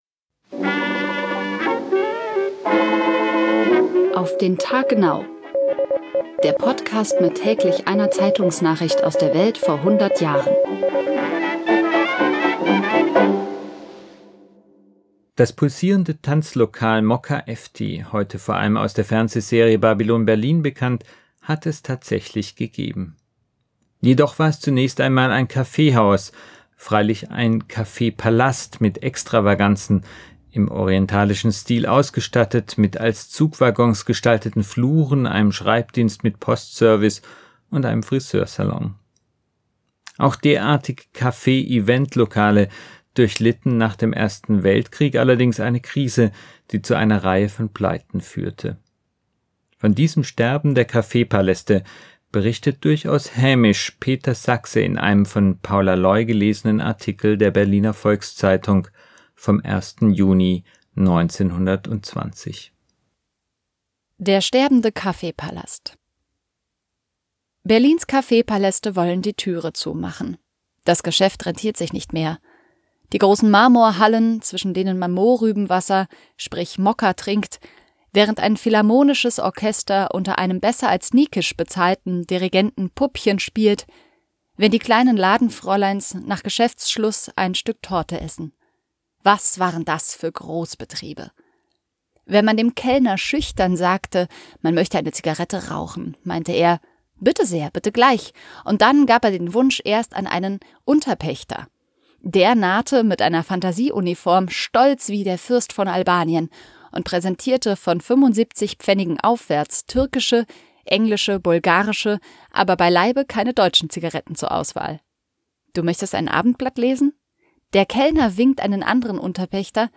gelesenen Artikel